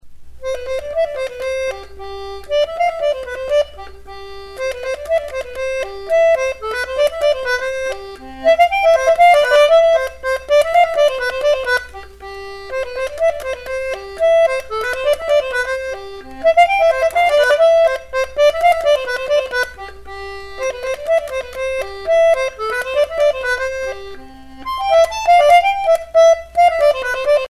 Traditional jig